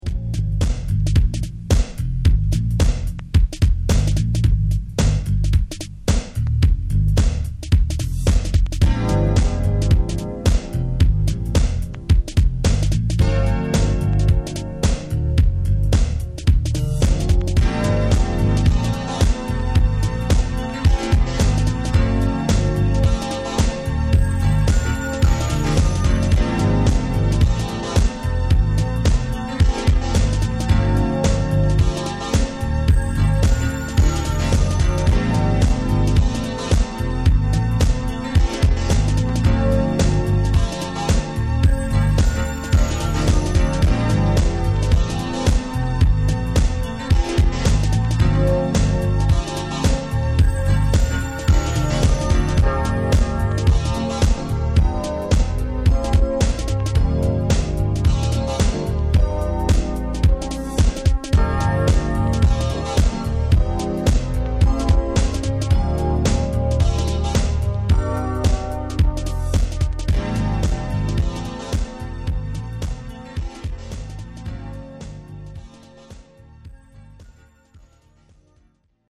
Going even further into the tape archives
Disco Soul Funk